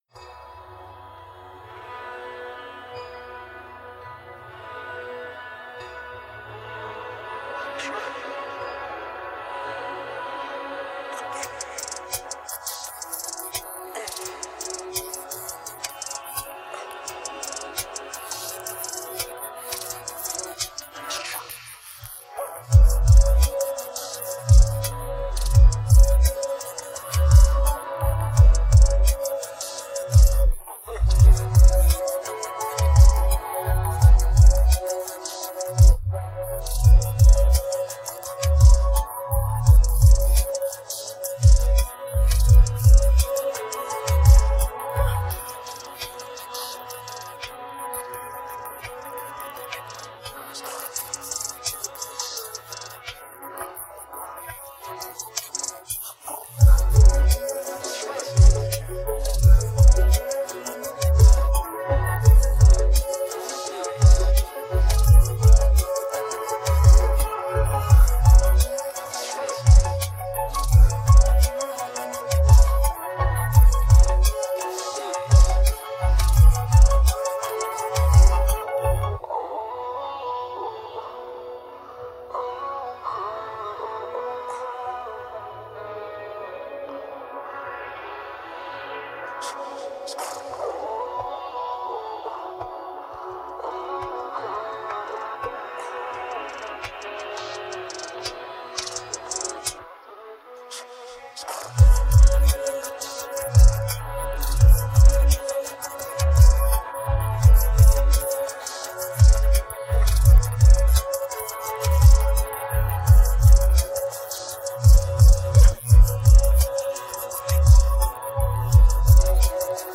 Play Karaoke & Rap with Us